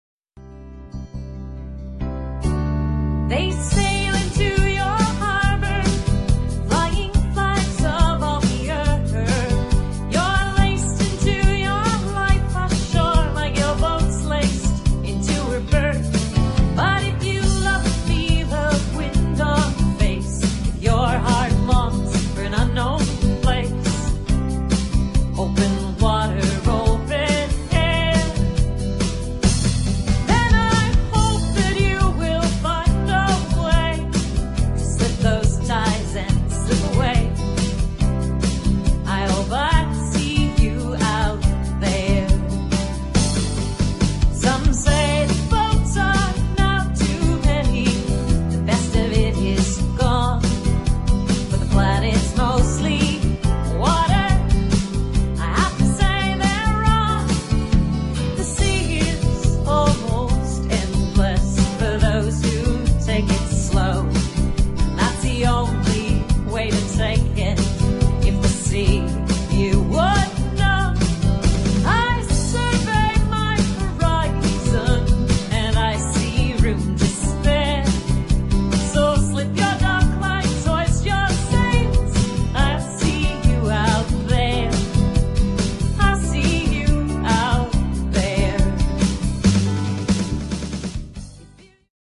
boating music